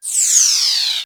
RetroGamesSoundFX
Shoot16.wav